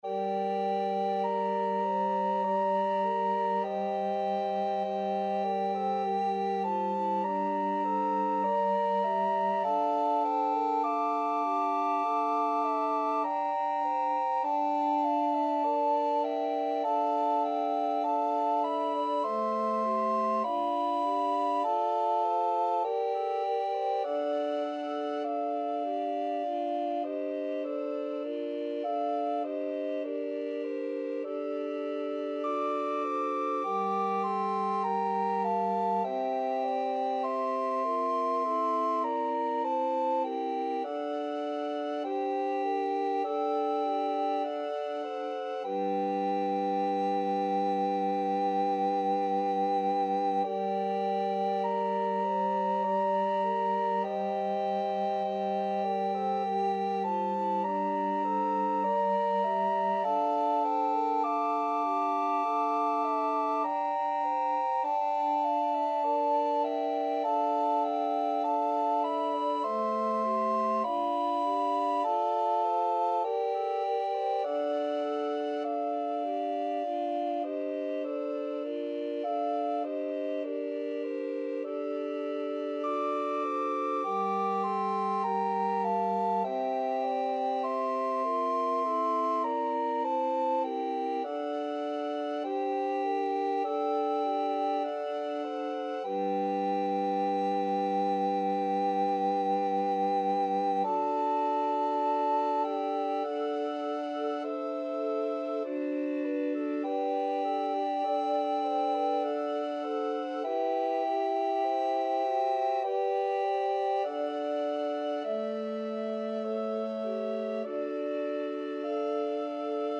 2/2 (View more 2/2 Music)
Classical (View more Classical Recorder Ensemble Music)